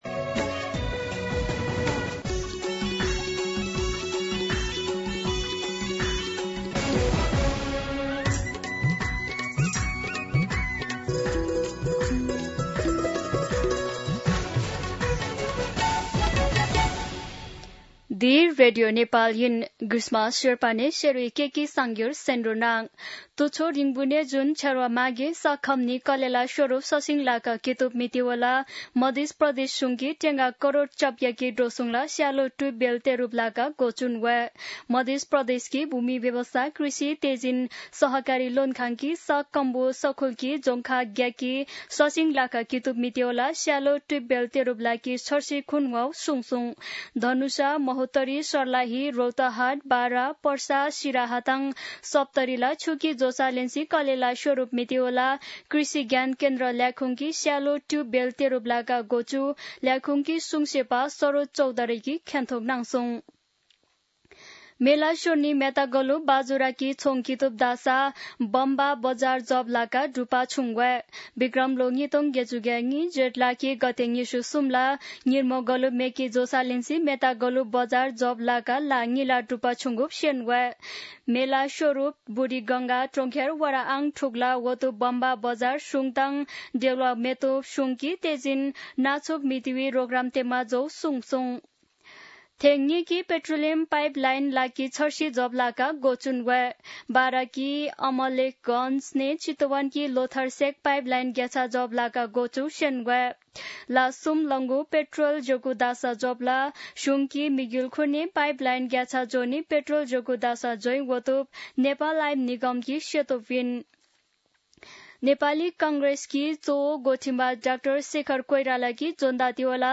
शेर्पा भाषाको समाचार : १७ साउन , २०८२
Sherpa-News-1.mp3